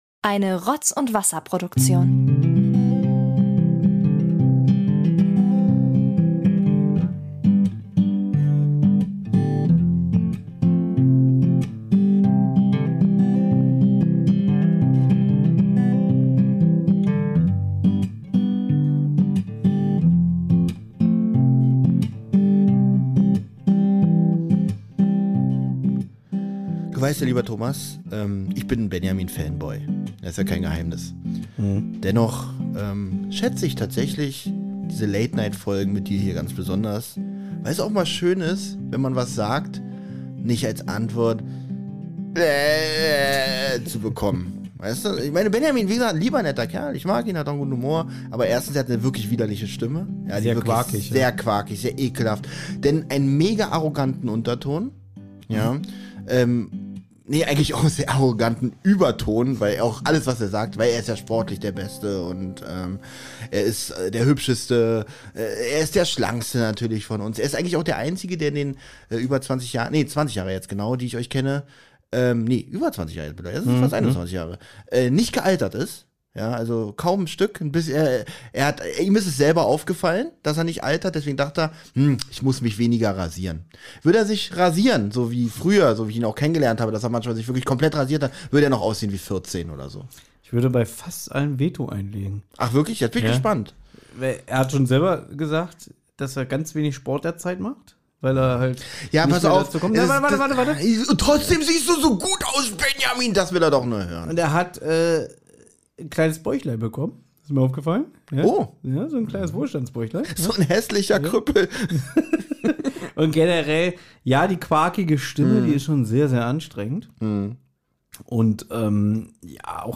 Herausgekommen ist eine entspannte, schon fast einschläfernde Folge.